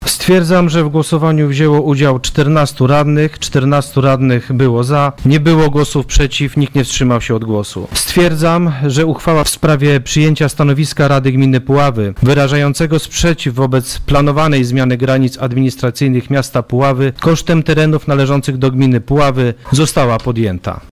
Wynik głosowania ogłosił przewodniczący Rady Gminy Puławy Tomasz Mizak.